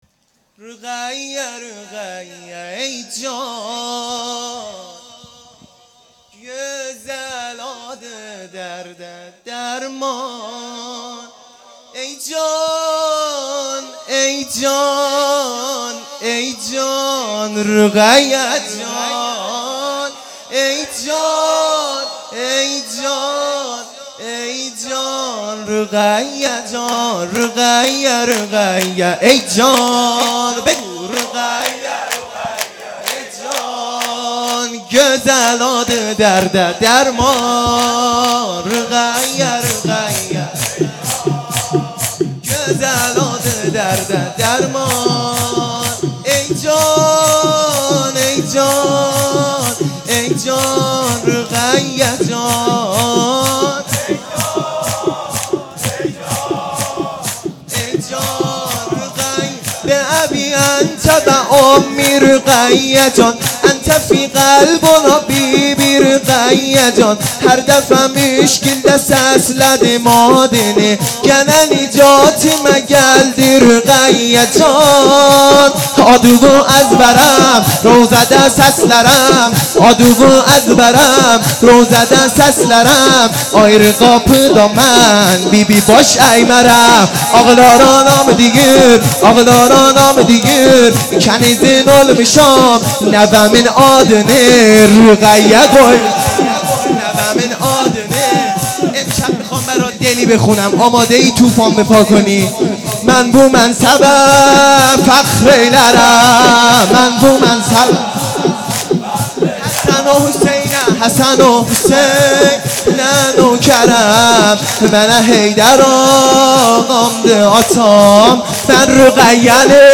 خیمه گاه - بیرق معظم محبین حضرت صاحب الزمان(عج) - شور ا رقیه رقیه ای جان